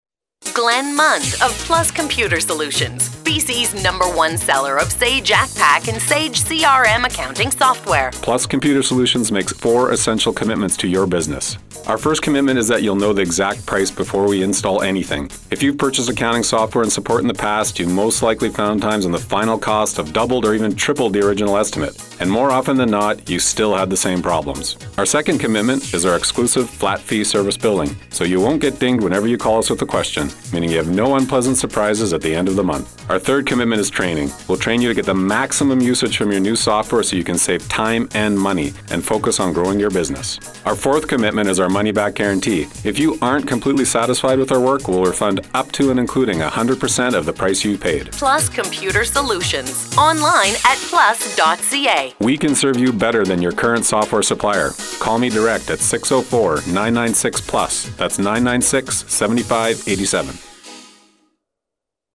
Attached was this radio ad they have been running.